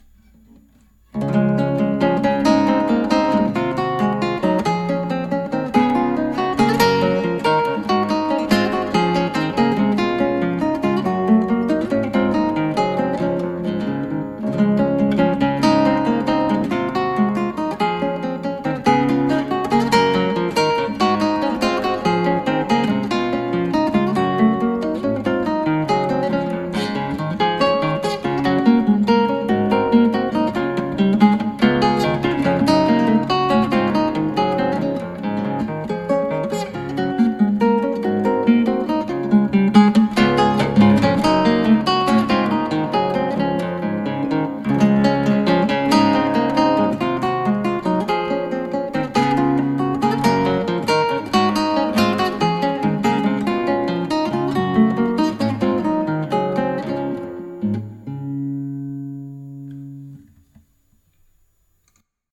クラシックギター　ストリーミング　コンサートサイト